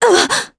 Isolet-Vox_Damage_jp_03.wav